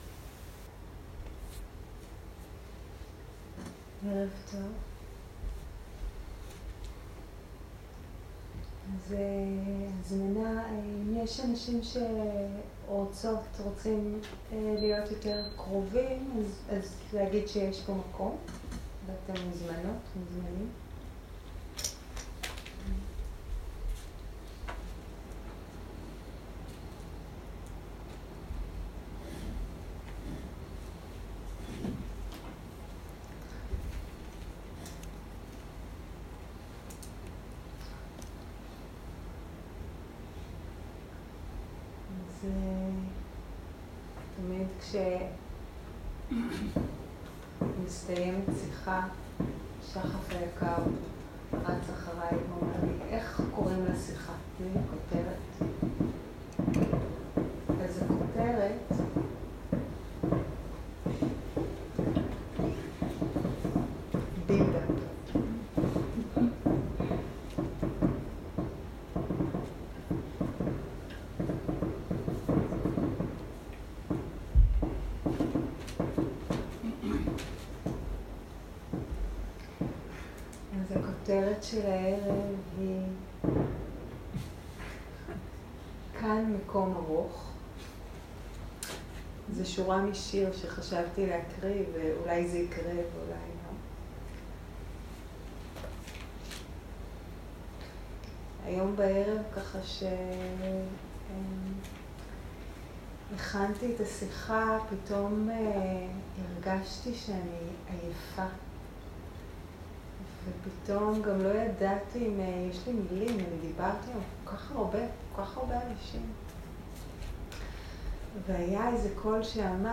Dharma Talks שפת ההקלטה